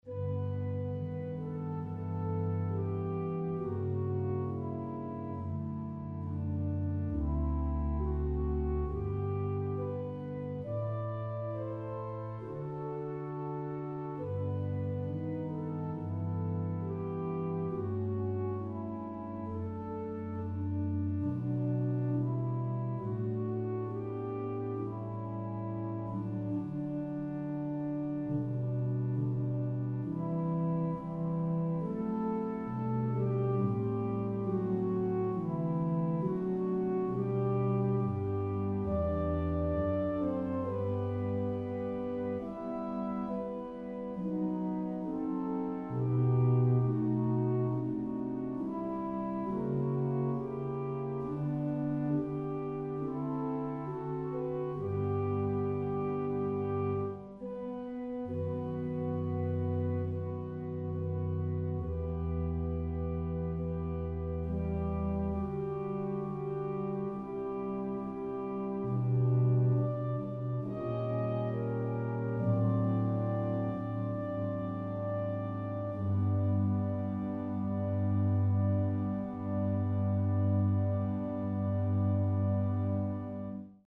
For the Ending Prayers of The Chaplet of the Divine Mercy, click the ▶ button to listen to an organ setting of the composition Ave Verum Corpus by the English composer Edward Elgar (1857-1954), or play the music in a New Window